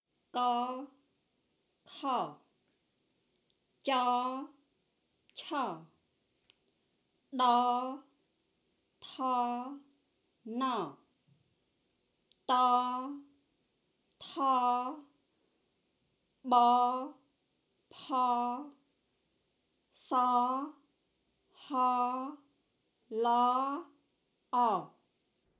Chacun de ces quatre fichiers vous propose l’ensemble des lettres de la série à la suite :
14 Consonnes du groupe 1 (“légères” / “insonores” / “série [â:]”)
Khmer_ConsonnesGroupe1.mp3